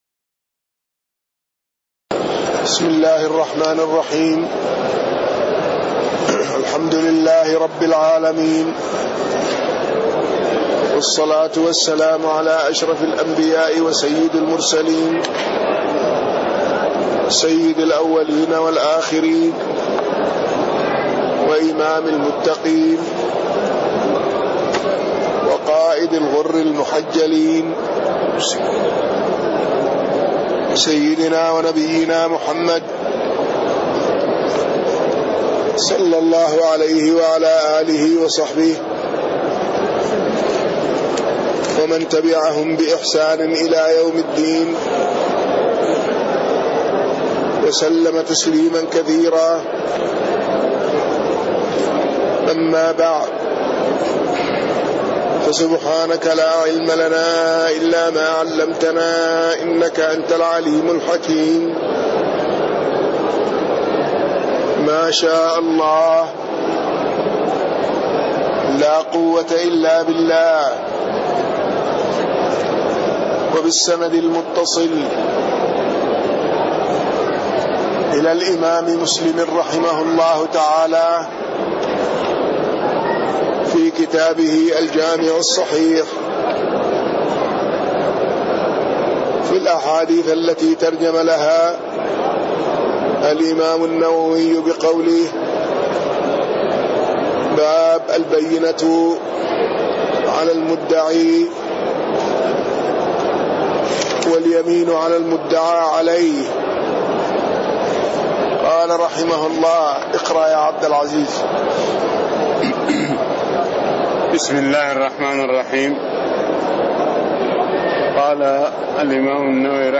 تاريخ النشر ٤ شعبان ١٤٣٥ هـ المكان: المسجد النبوي الشيخ